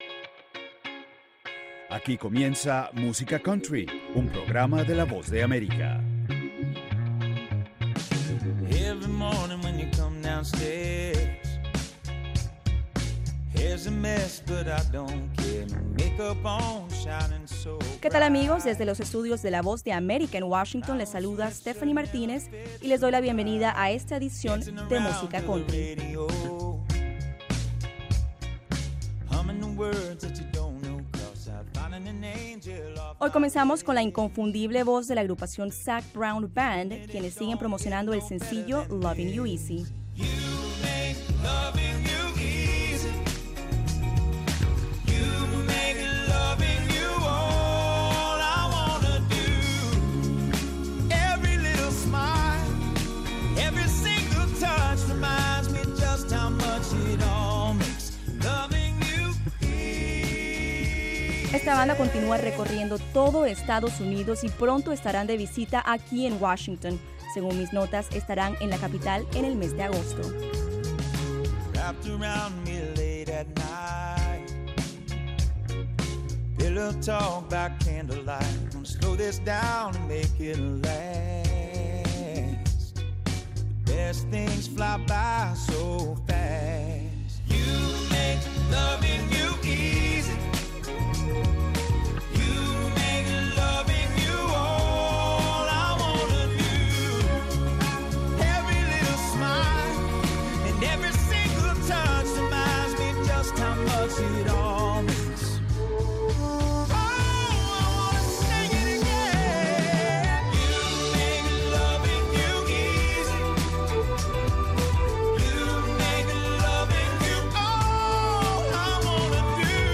Música Country